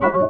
Key-organ-03_005.wav